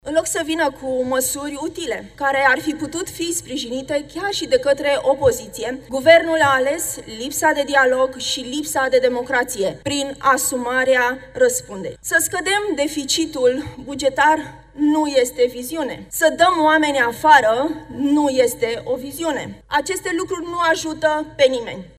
După ce la prima moțiune a refuzat să intre în sală, la dezbaterea celei de-a doua moțiuni de cenzură. lidera POT, Anamaria Gavrilă a luat cuvântul la tribuna Parlamentului.